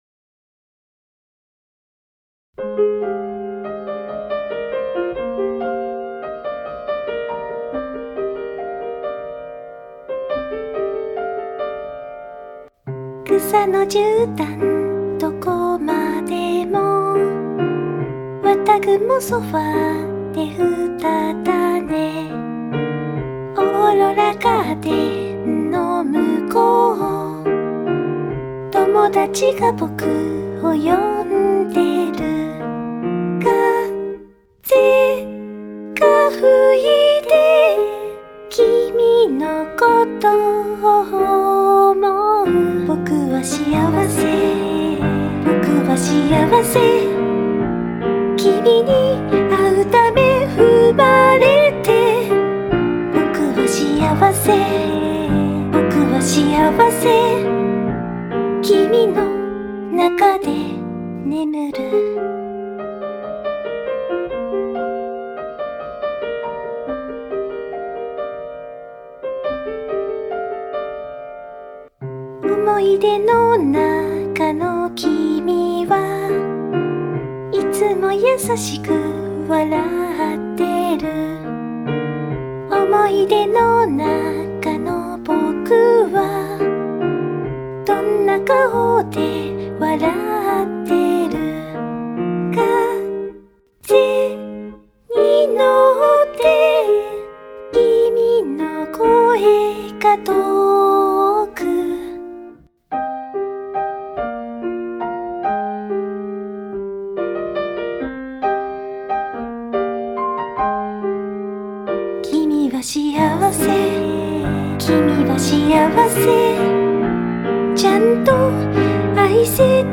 【Vocal / リマスター版2025】 mp3 DL ♪